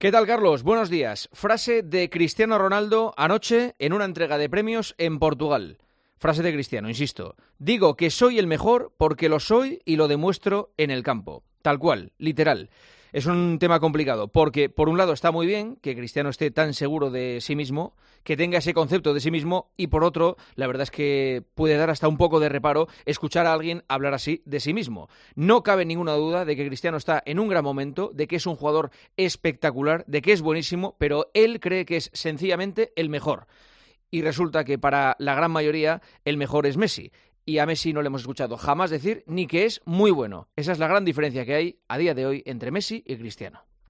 El comentario de Juanma Castaño 'EL PARTIDAZO' DE COPE